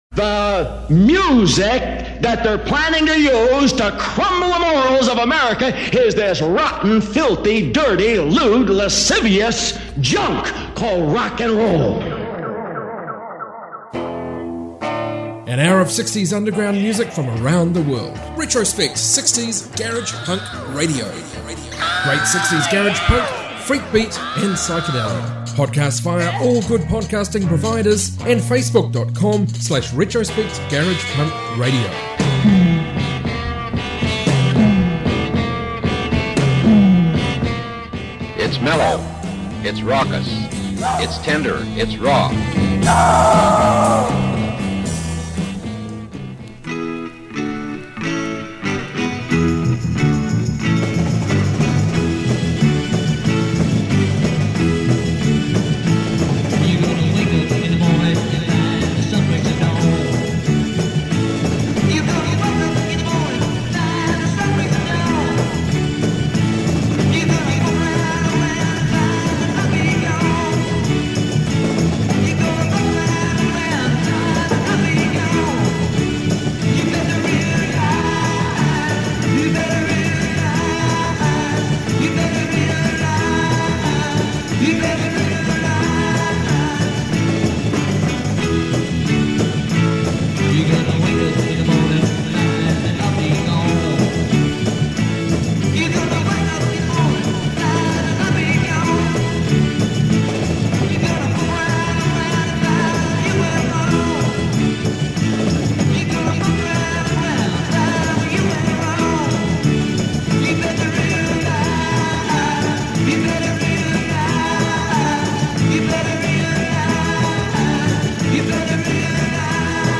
60s garage punk proto-punk garage rock freak beat